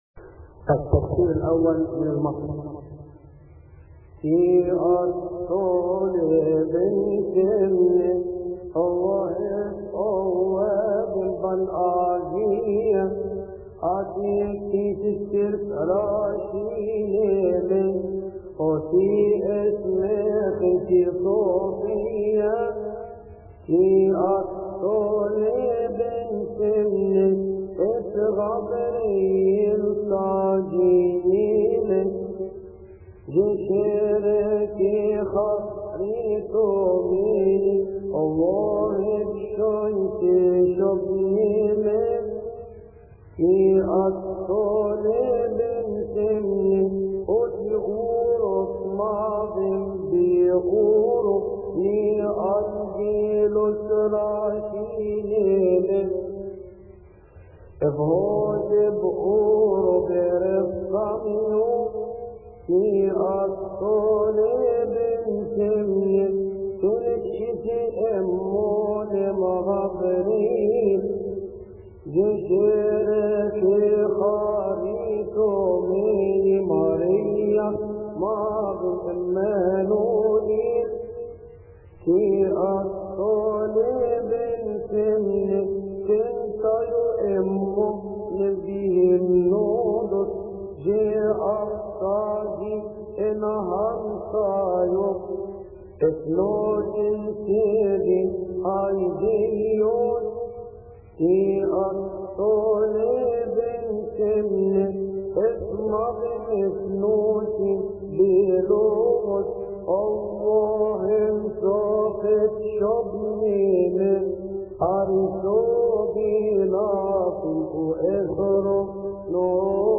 التفسير الأول من المصري لثيؤطوكية السبت يصلي في تسبحة عشية أحاد شهر كيهك